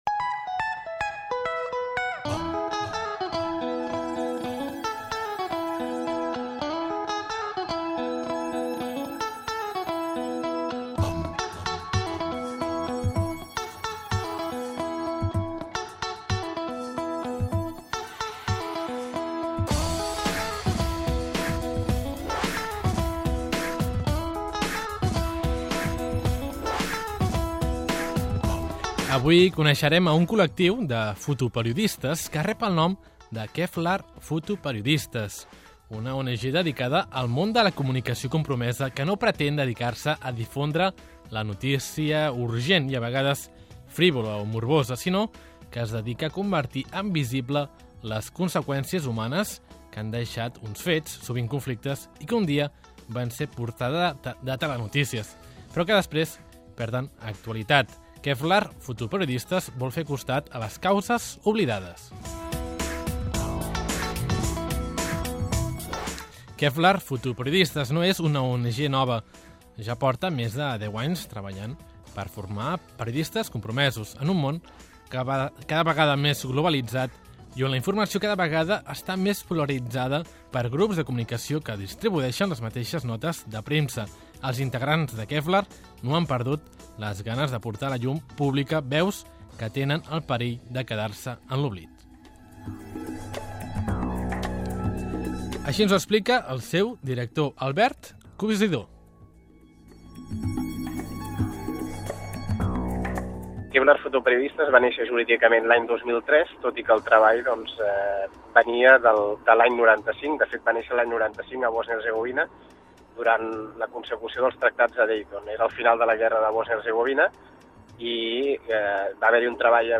REPORTAJE sobre Kvlar Fotoperiodistes